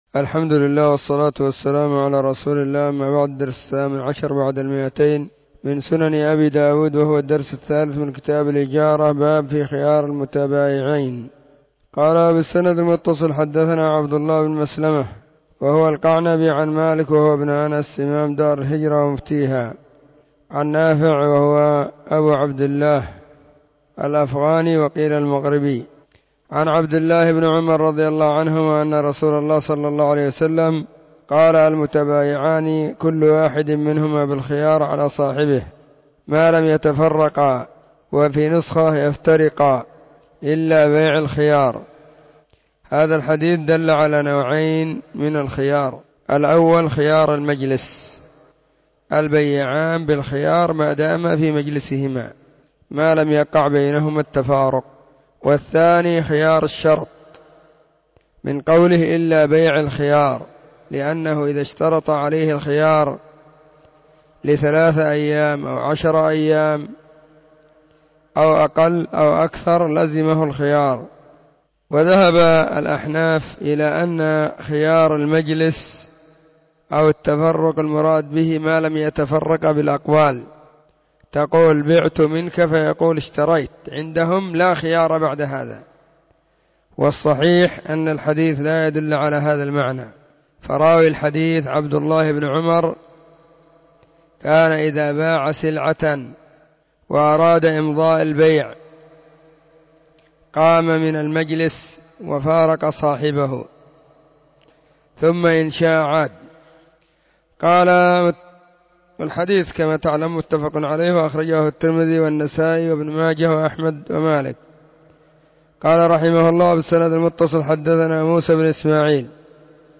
🕐 [بعد صلاة العصر في كل يوم الجمعة والسبت]
📢 مسجد الصحابة بالغيضة, المهرة، اليمن حرسها الله.